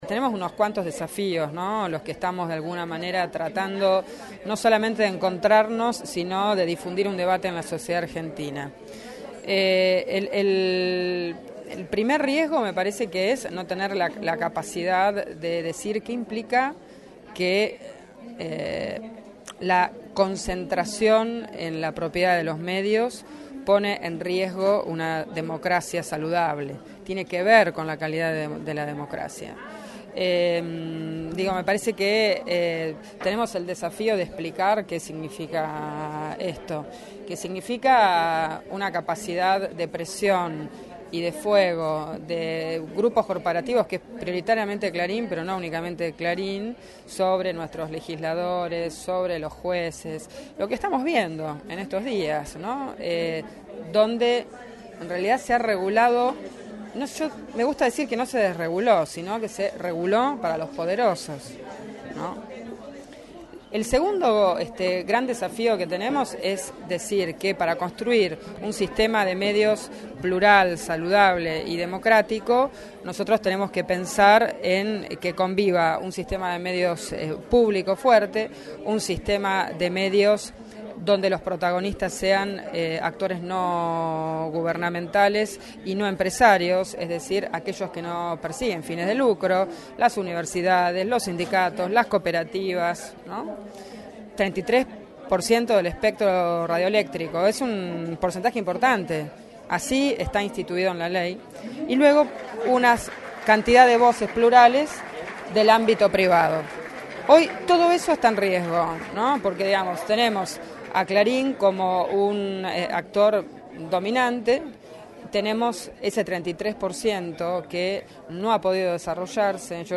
Testimonios desde el Primer Congreso Nacional de la Coalición por una Comunicación Democrática